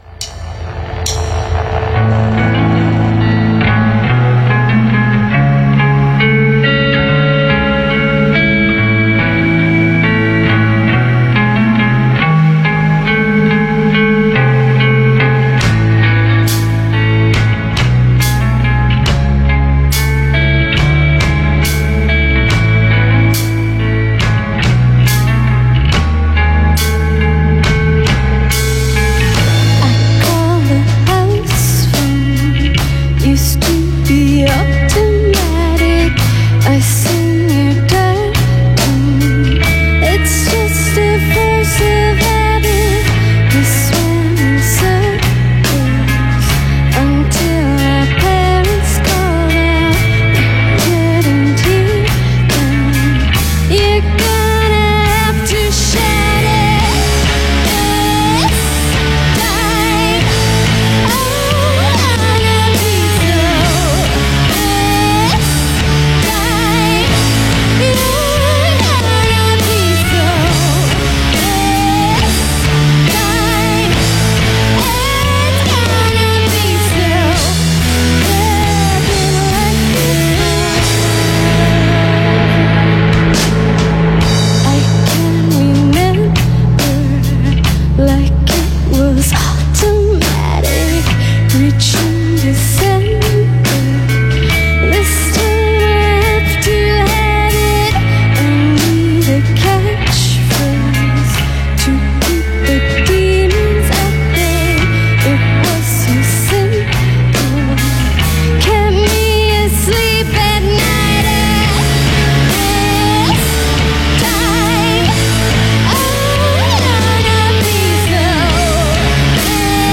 vocals, bass
guitar, vocals